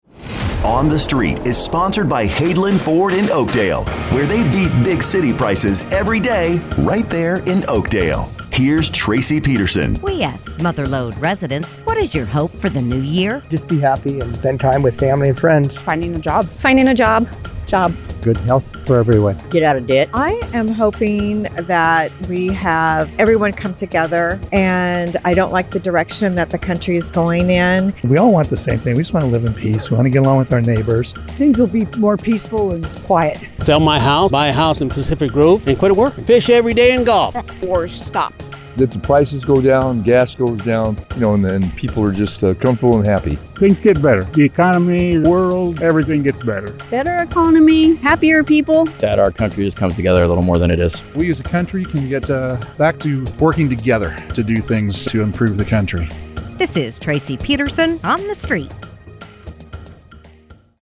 asks Mother Lode residents, “What is your hope for the New Year?”